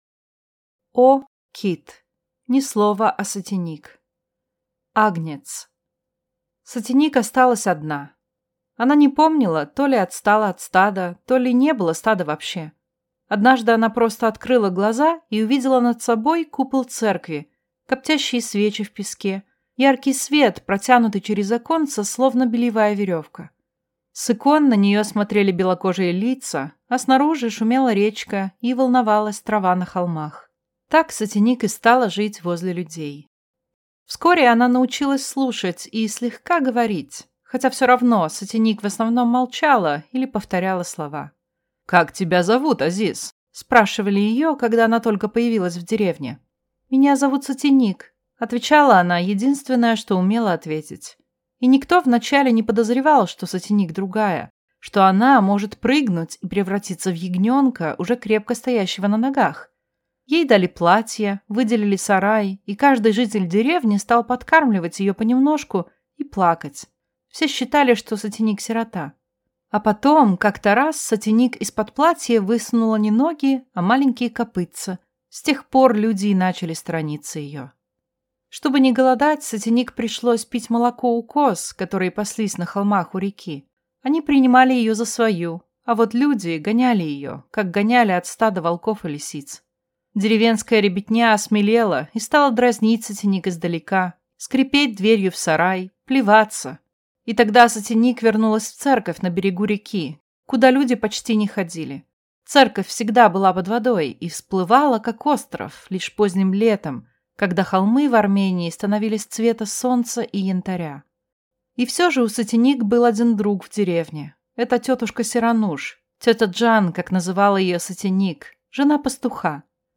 Аудиокнига Ни слова о Сатеник | Библиотека аудиокниг